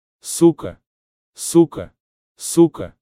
FEMALE DOG - SUKA (SOO-ka), СУКА, if you want to emphasize sex